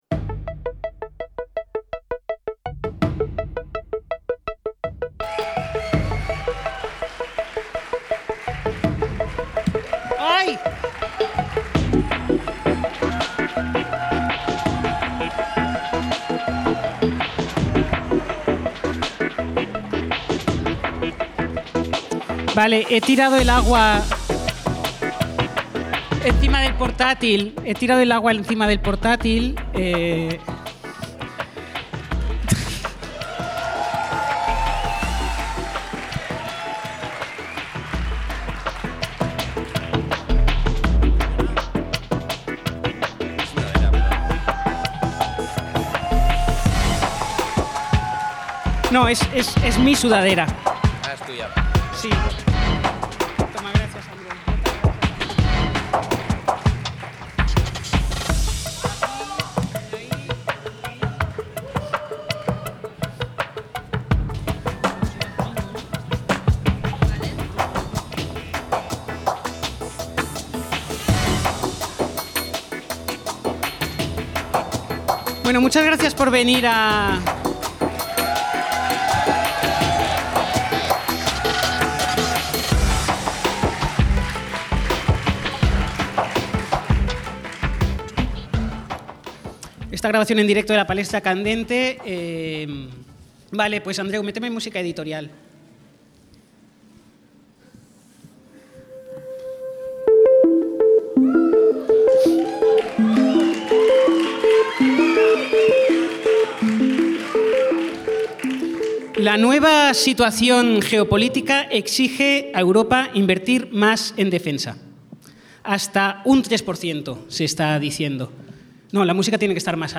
palestrazo-live-podcast-EMTPodcast.mp3